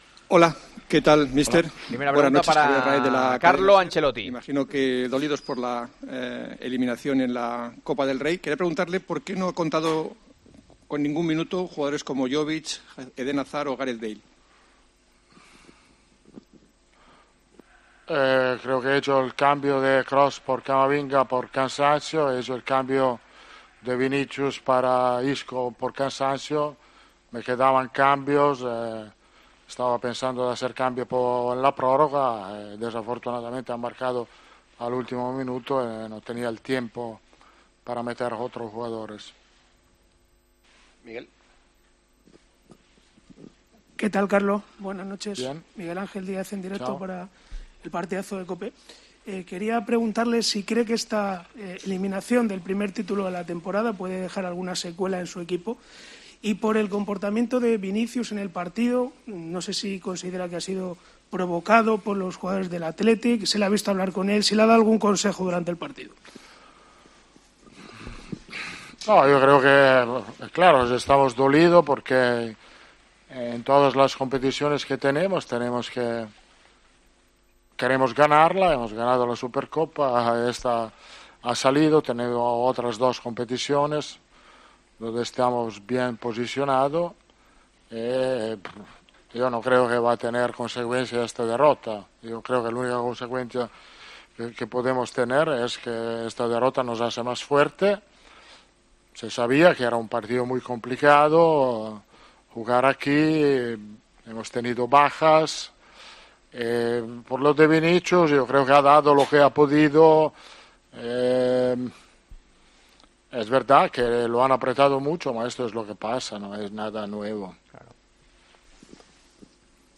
EN RUEDA DE PRENSA
El técnico italiano ha valorado en rueda de prensa la eliminación copera de su equipo a manos del Athletic.